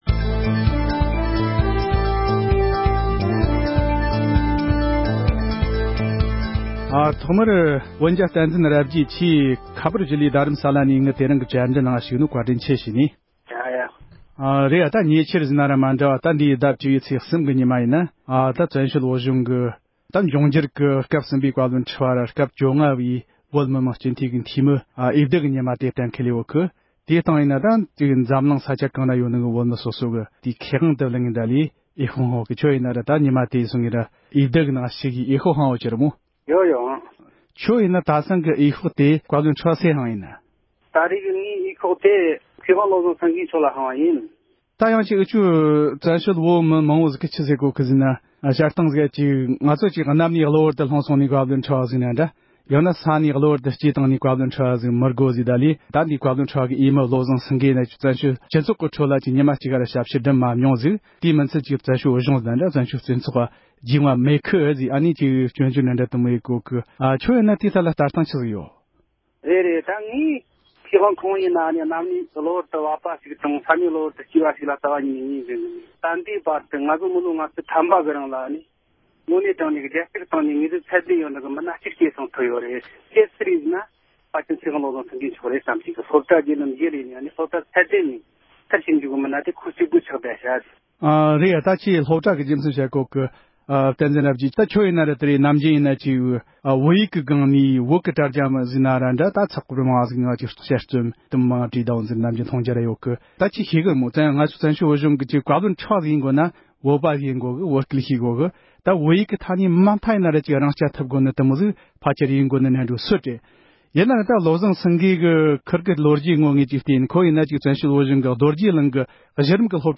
༢༠༡༡ལོའི་བཀའ་བློན་ཁྲི་པའི་འོས་མི་གྲས་སུ་ཡོད་པའི་མཁས་དབང་བློ་བཟང་སེང་གེ་ལ་བཀའ་ཁྲིའི་ཁྱད་ཆོས་ཚང་མིན་སྐོར་བགྲོ་གླེང༌།